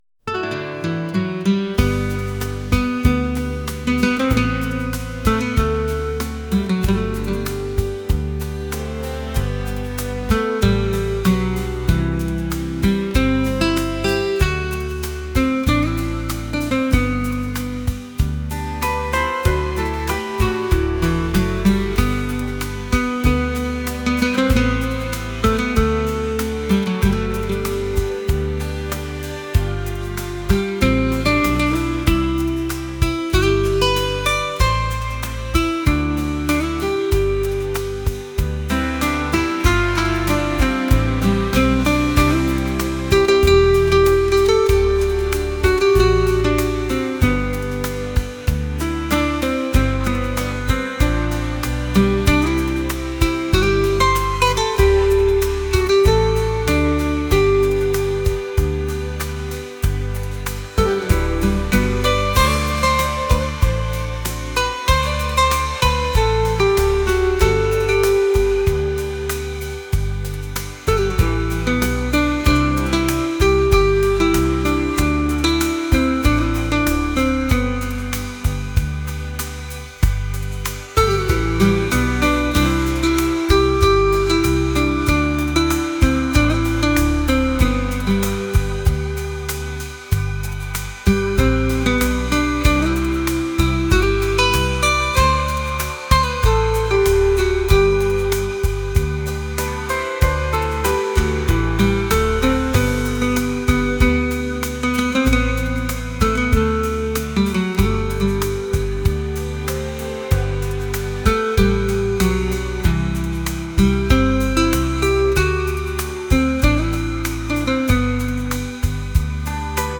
pop | acoustic | soul & rnb